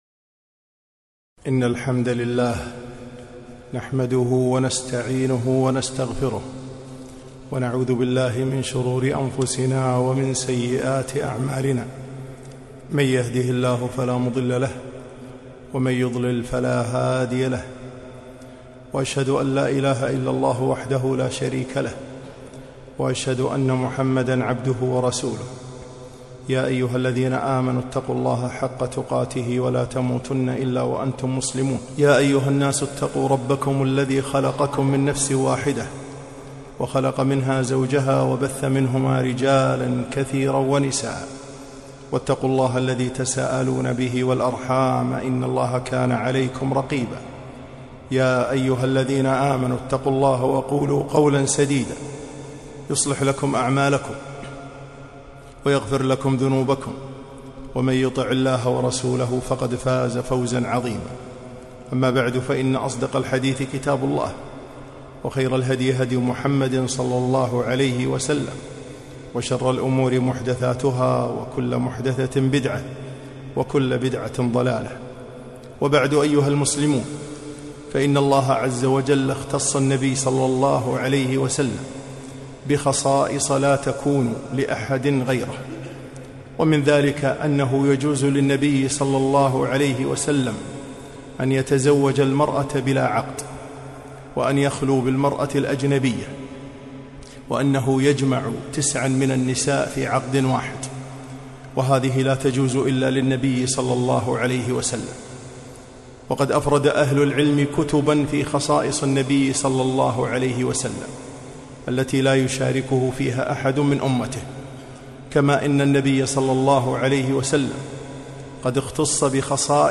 خطبة - من خصائص النبي ﷺ